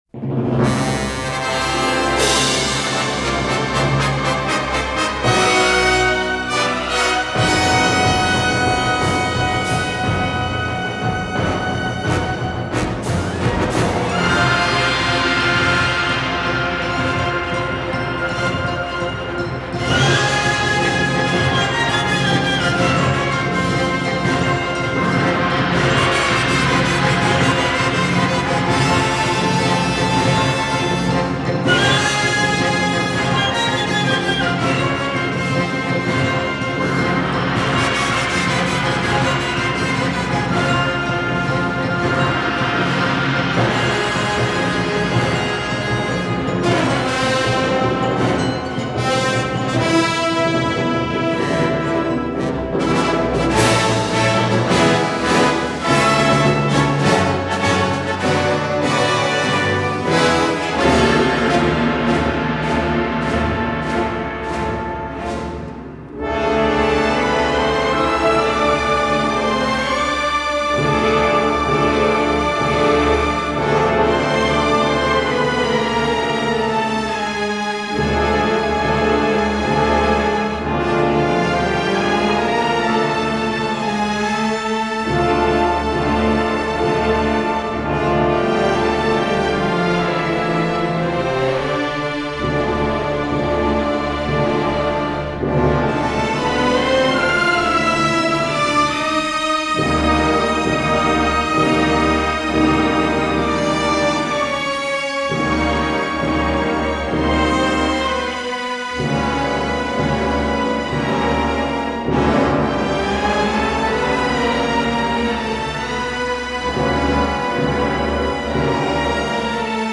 Classical, Soundtrack, Score